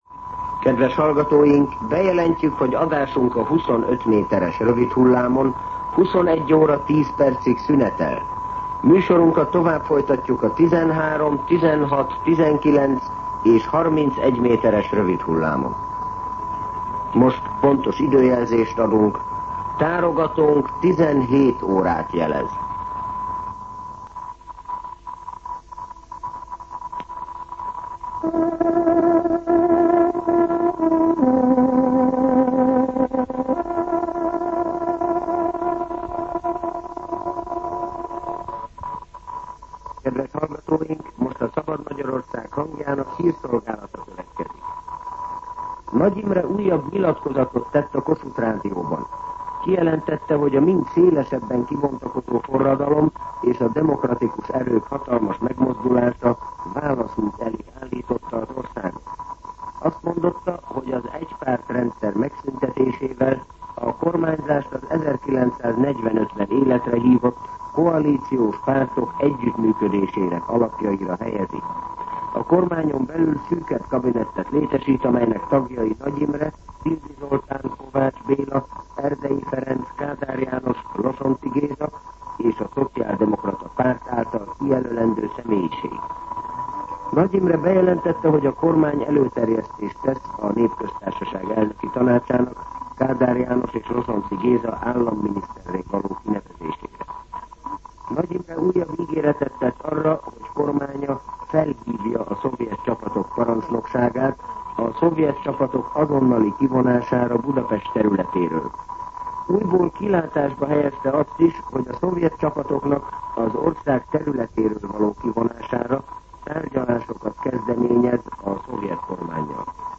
17:00 óra. Hírszolgálat